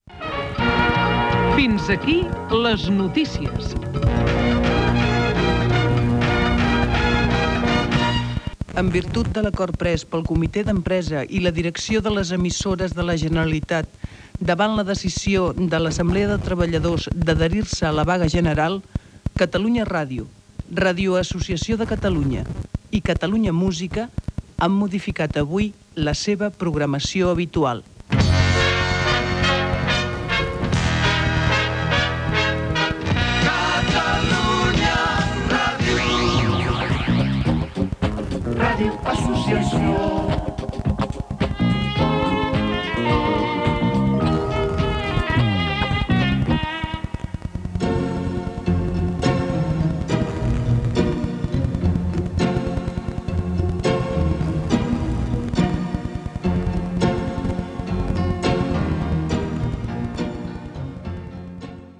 Careta de sortida de "Les notícies" i lectura del comunicat d'adhesió de les emissores Catalunya Ràdio, RAC i Catalunya Música a la Vaga general.
FM